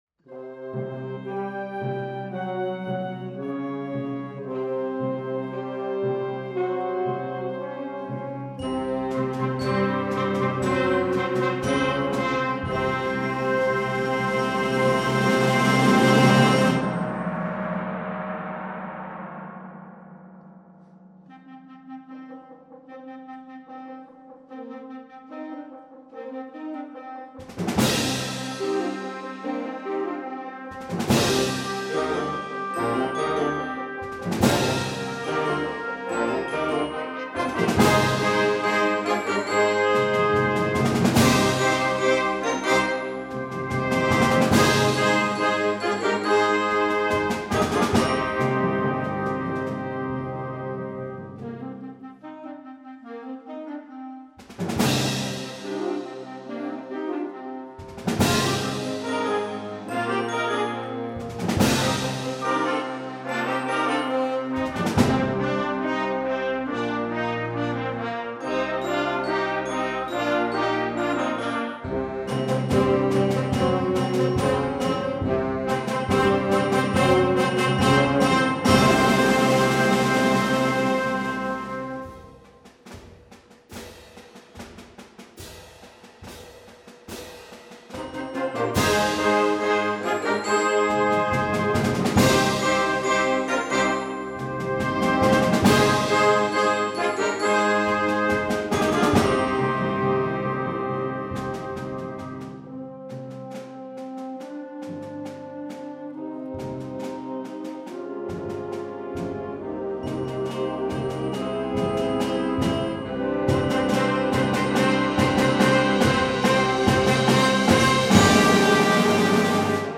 Gattung: Konzertwerk für Flexible Band/String Ensemble
Besetzung: Blasorchester